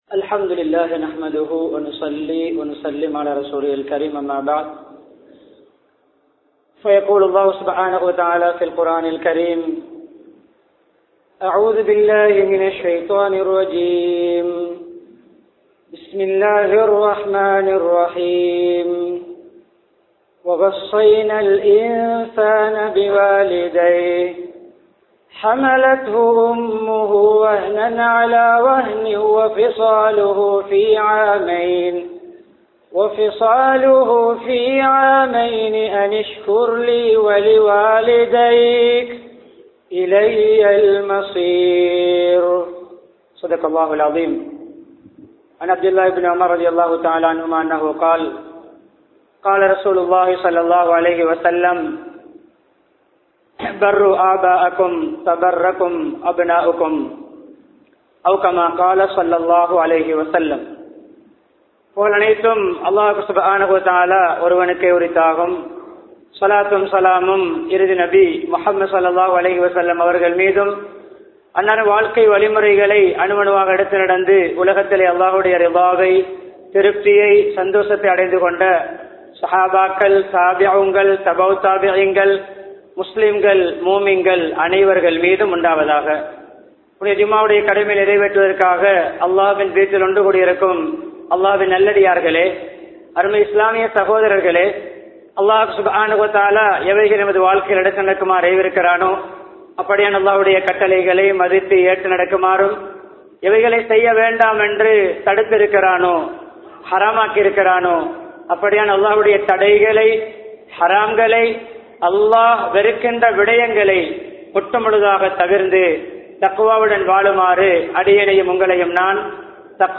பெற்றோருடன் நன்றியுடன் நடப்போம் | Audio Bayans | All Ceylon Muslim Youth Community | Addalaichenai